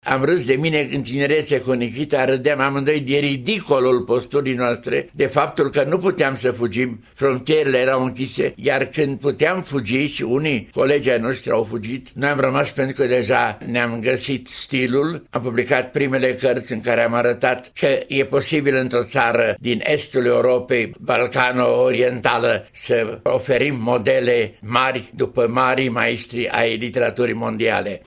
Romancier, eseist, poet, dramaturg şi publicist cu o carieră literară de peste 60 de ani, Nicolae Breban rememorează o perioadă care l-a definit: